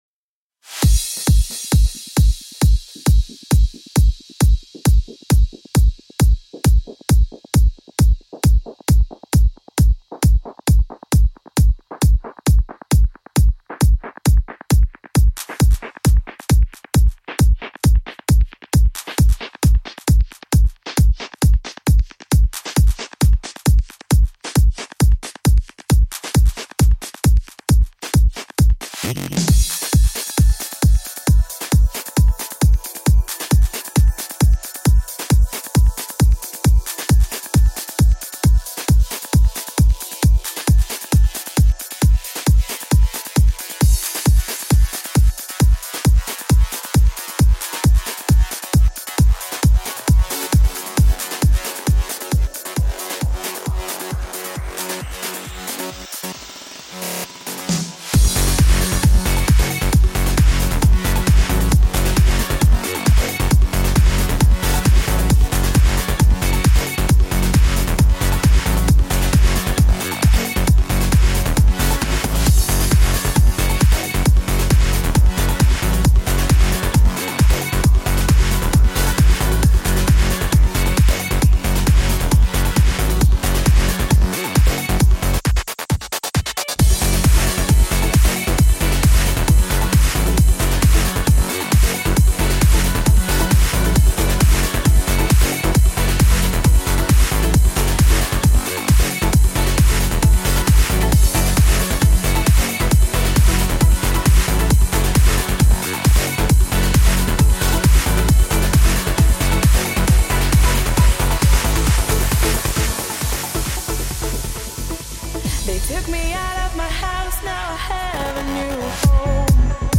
This is my first attempt to try some Trance 2.0 stuff.
Background Bells
FM sound in the first verse
additional choir in main part
Rock Snare
dirty basses